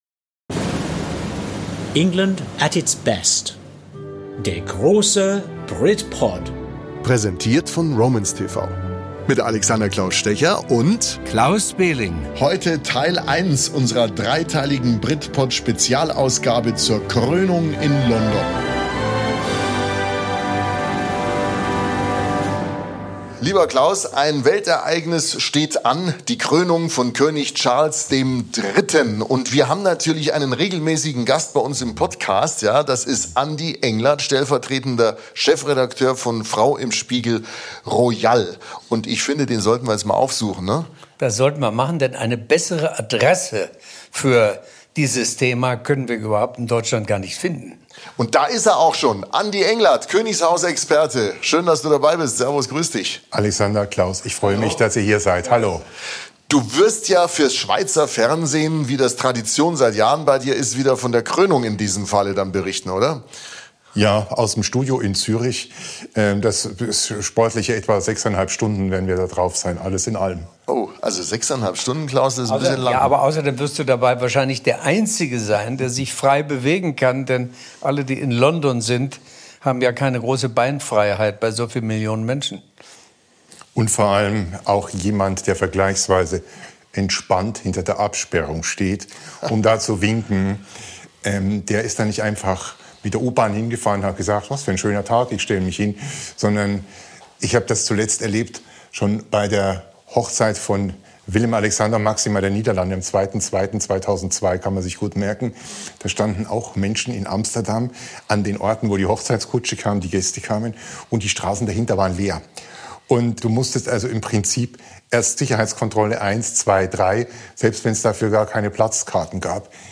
BRITPOD Spezial zur Krönung von König Charles III.: Teil 1 von den Vorbereitungen und der Atmosphäre in London.